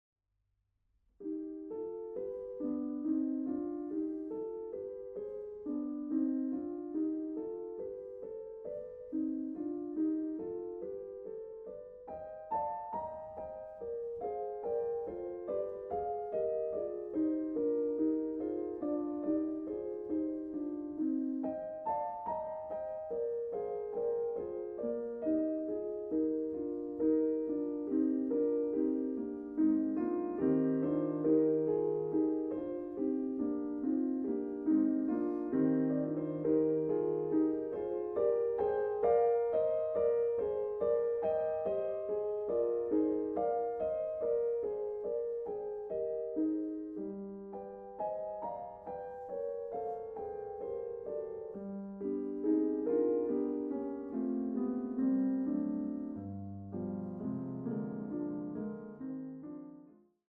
A piano journey through various European musical worlds
Piano